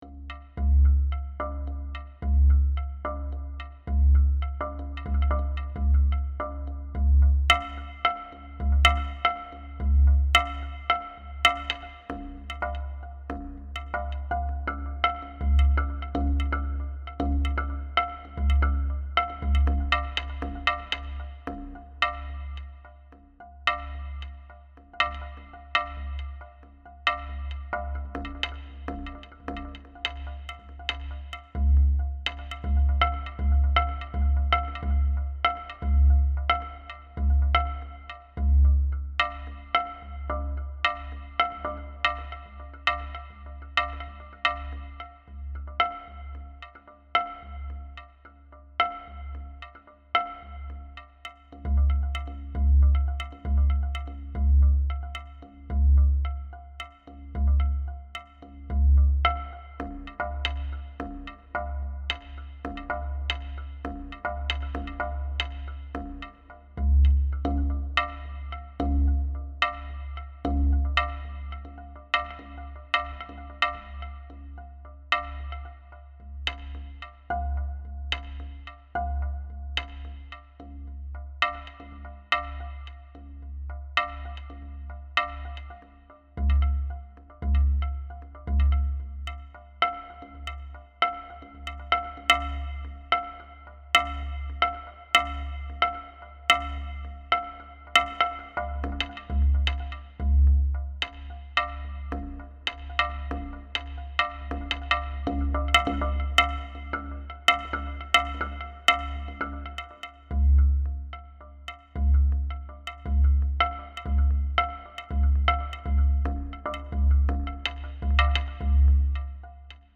Marimba (Excerpt)
A preliminary study for Expansor, a composition for a single marimba bar
The algorithm is  written in Supercollider programming language and chooses between the different timbres (marimba bar areas), accents and durations.